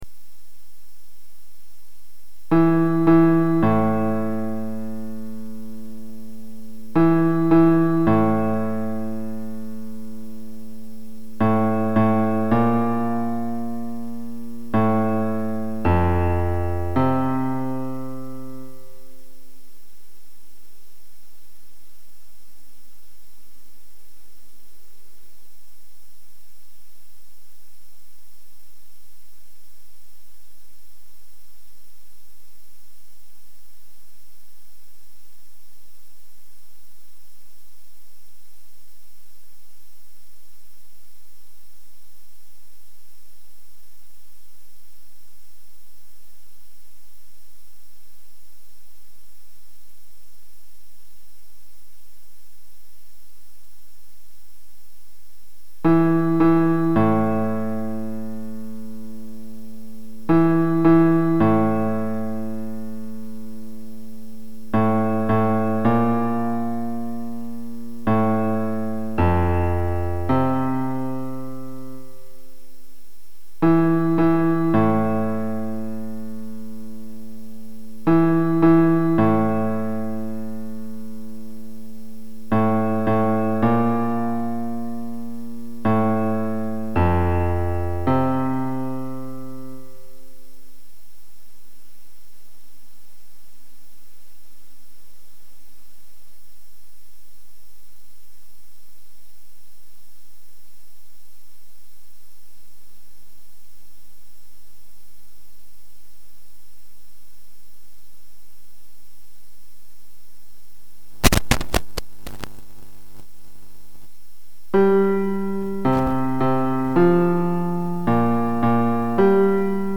shangri-la-bass.mp3